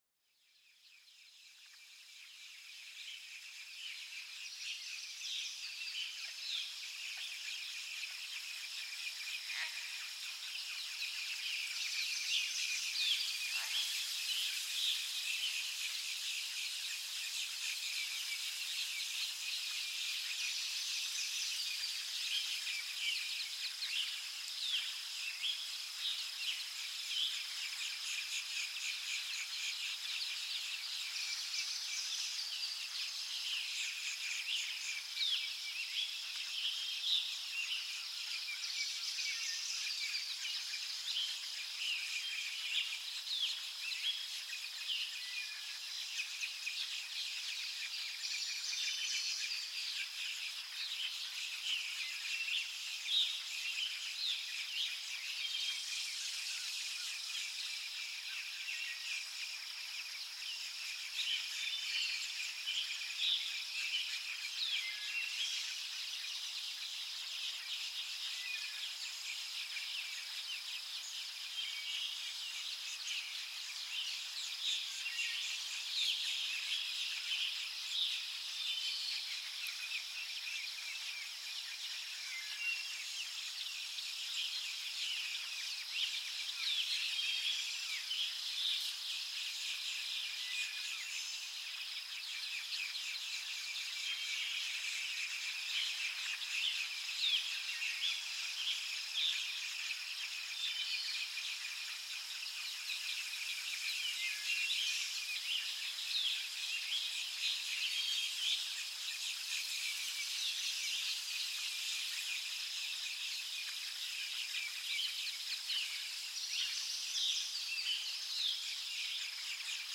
Dans cet épisode unique, plongez au cœur d'une forêt vibrante, où le chant des oiseaux se déploie en une mélodie enchanteresse. Laissez-vous transporter par la douce symphonie de la nature, un concert sans pareil où chaque note éveille sérénité et émerveillement.